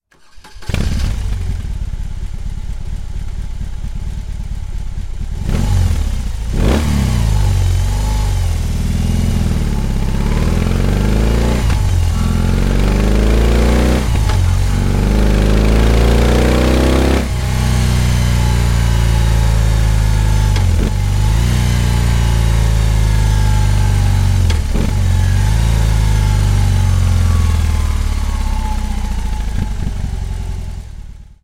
De la 2.000 la 4.000 rpm, un cuplu de peste 150 Nm este disponibil în orice moment, şi această forţă de tracţiune absolută este combinată cu un sunet plin şi melodios.
Iată-i “glasul”:
K34_R18_ECE_Serien-AGA.mp3